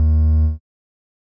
Techmino/media/sample/bass/7.ogg at beff0c9d991e89c7ce3d02b5f99a879a052d4d3e
添加三个简单乐器采样包并加载（之后用于替换部分音效）